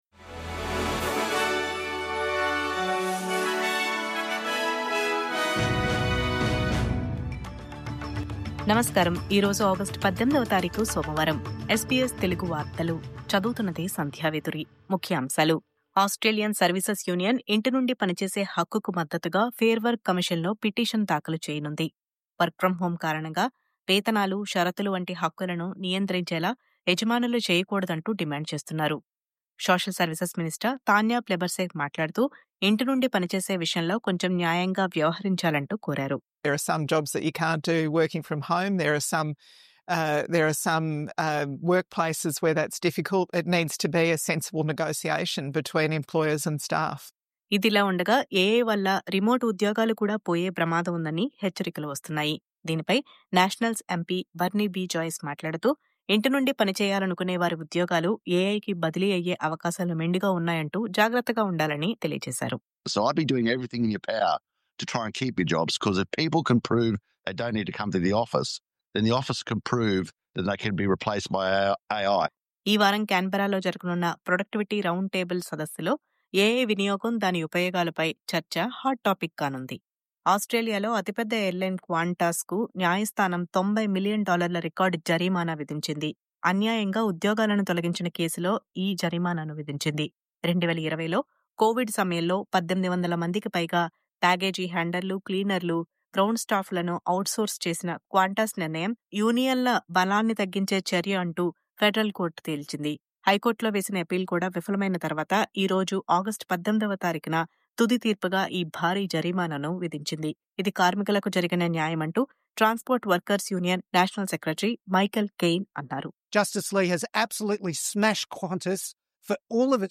News Update : అన్యాయంగా గ్రౌండ్ స్టాఫ్ ఉద్యోగాలు తొలగించినందుకు.. Qantas‌కు 90 మిలియన్ డాలర్ల జరిమానా..